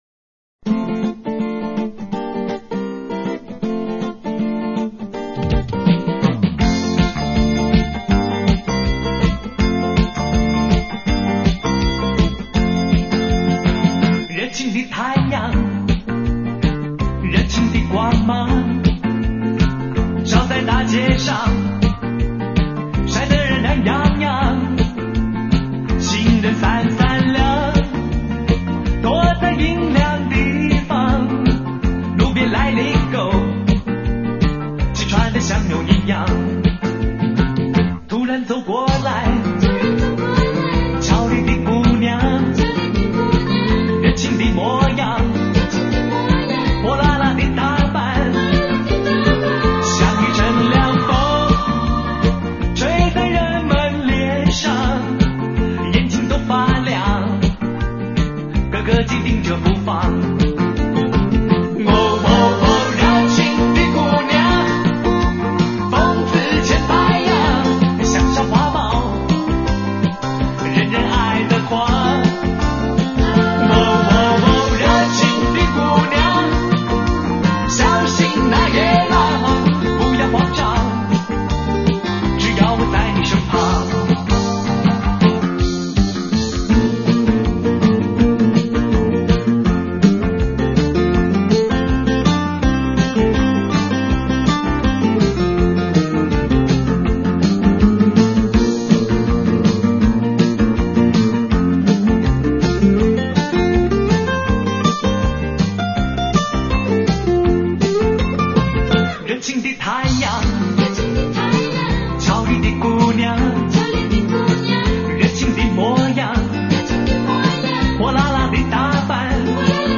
挑动心灵深处的快节奏、跟着记忆再舞一段往日的青春、国语歌坛最经典的动感偶像、